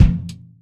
Kick14.wav